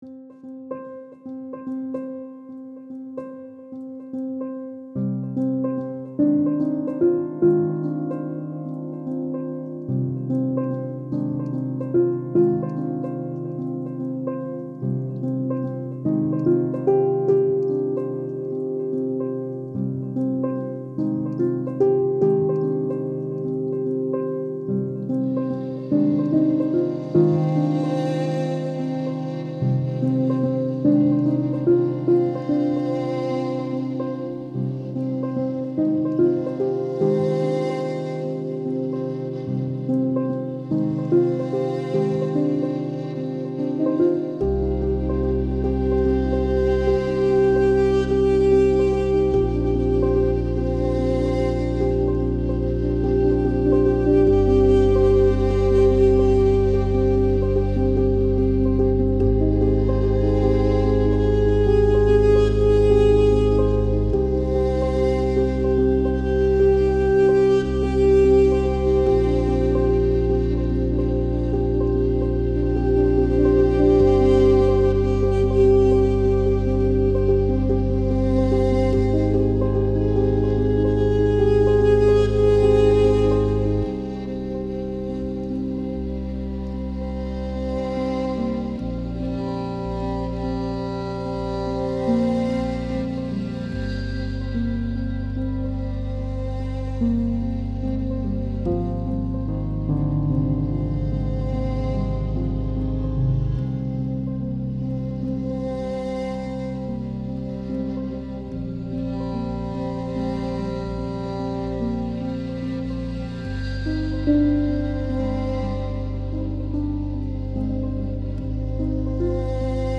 modern classical / classical crossover single